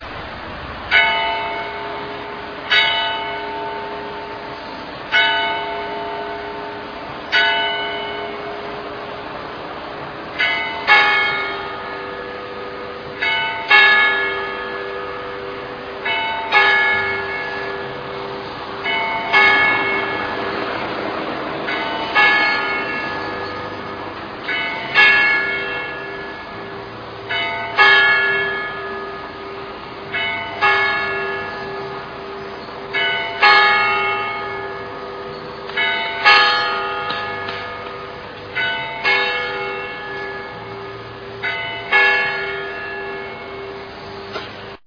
Tuomiokirkon kello
lyö kaksitoista 12.7.1997 (mp3).